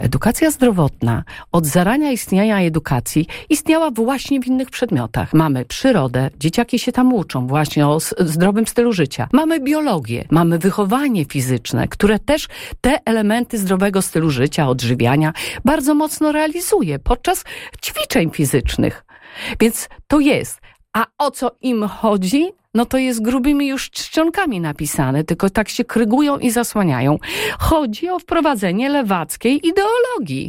Parlamentarzystka, mająca za sobą wiele lat pracy jako nauczycielka i dyrektor szkoły, mówiła na antenie Radia Nadzieja, że wiele zagadnień z podstawy programowej tego przedmiotu było już realizowane w szkołach.